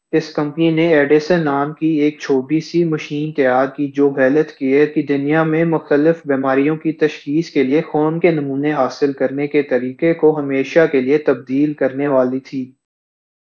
deepfake_detection_dataset_urdu / Spoofed_TTS /Speaker_13 /128.wav